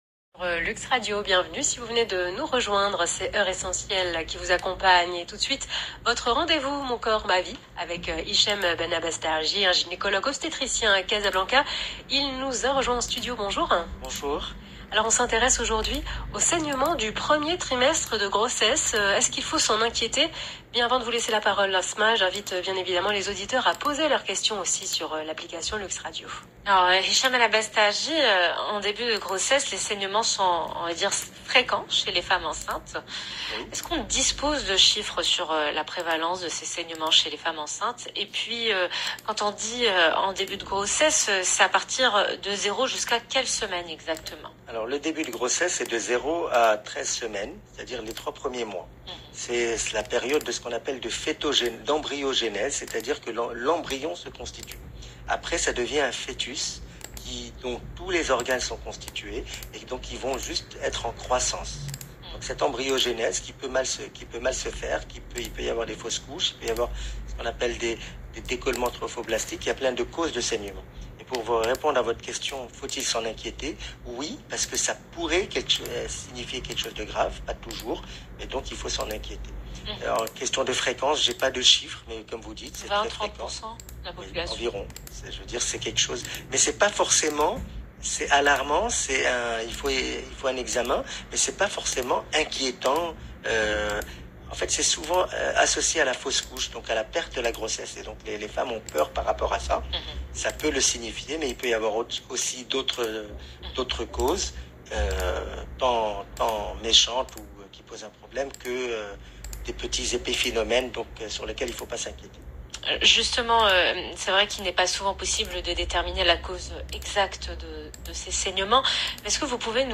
Explication dans cette interview de l’heure essentielle sur Luxe Radio du 04 Mai 2021